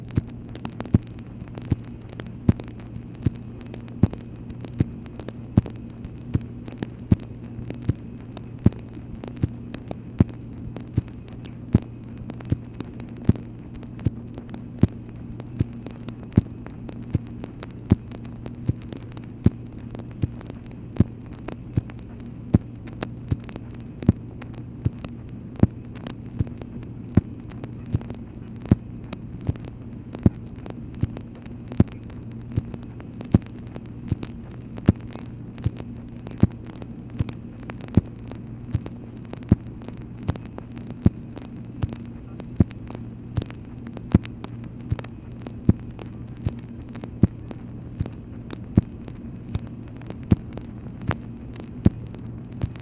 MACHINE NOISE, November 12, 1965
Secret White House Tapes | Lyndon B. Johnson Presidency